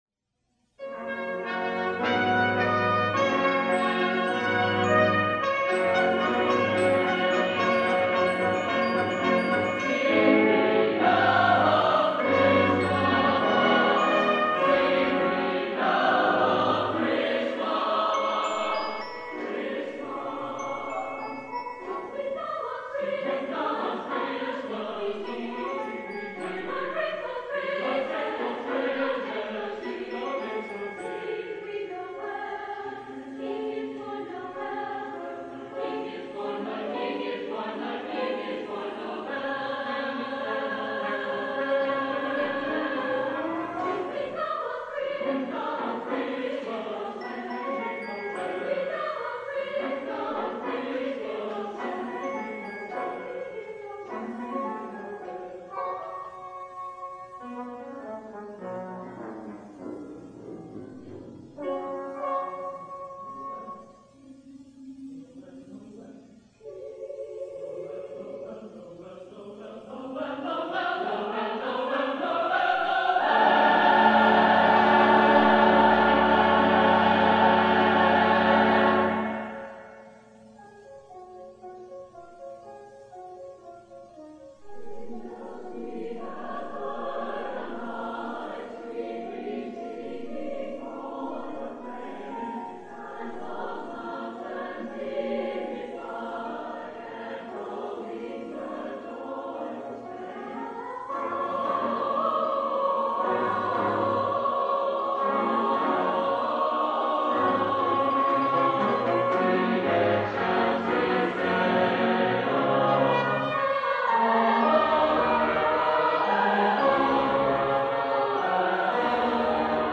Southern Nazarene University presents the SNU Choral, Brass Choir and Choral Society in their annual Christmas concert : Christmas Sounds.